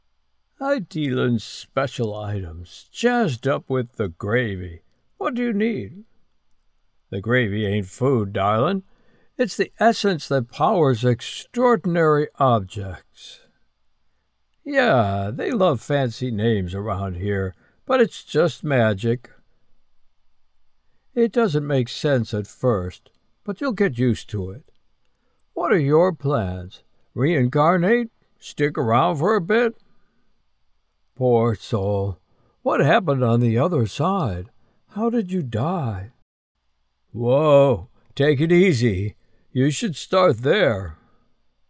Heavenly Angel Character
English - Midwestern U.S. English
Middle Aged
My very quiet home studio is equipped with a Neumann TLM 103 microphone and other professional gear with high speed internet for smooth audio delivery!